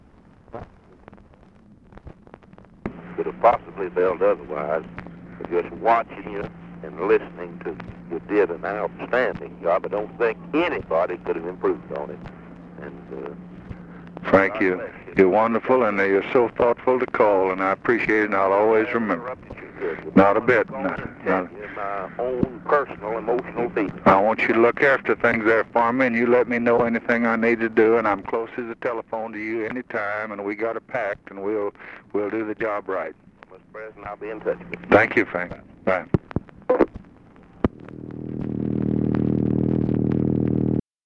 Conversation with FRANK CLEMENT, November 28, 1963
Secret White House Tapes